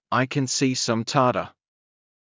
ｱｲ ｷｬﾝ ｼｰ ｻﾑ ﾀｰﾀｰ